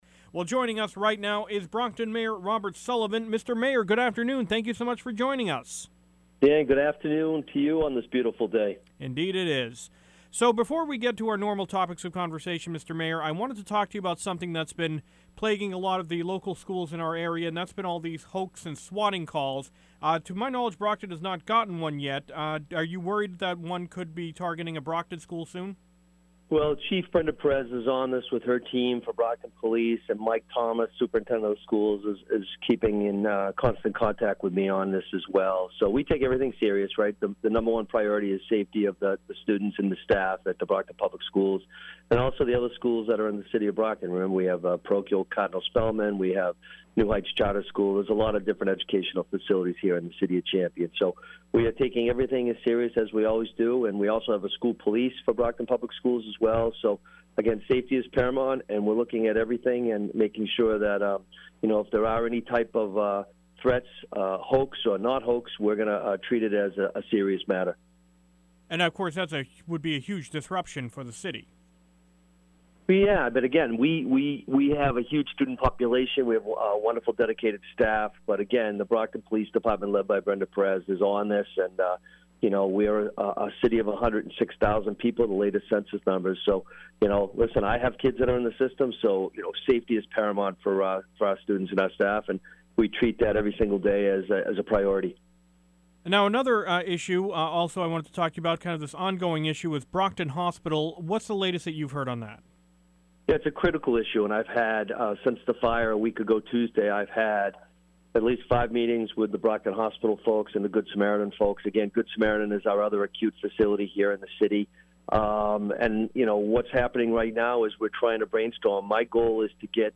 Brockton Mayor Discusses Swatting, Brockton Hospital, and COVID